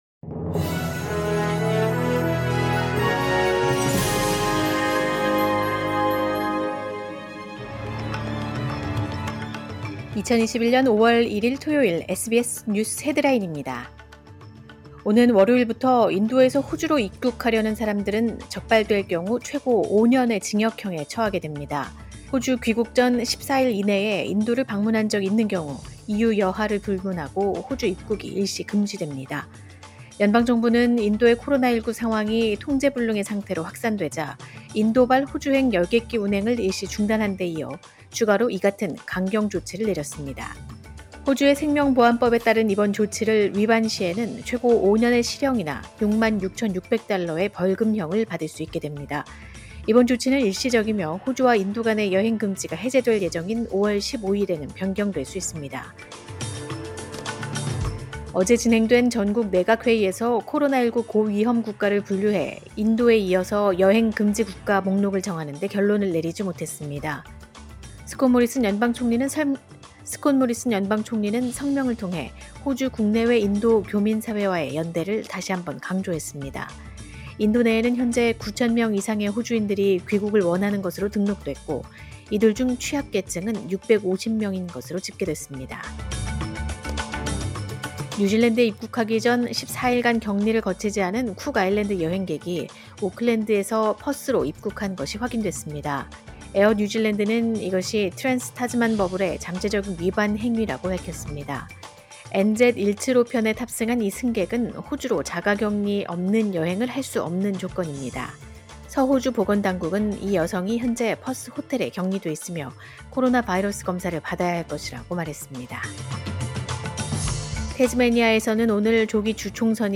2021년 5월 1일 토요일 SBS 뉴스 헤드라인입니다.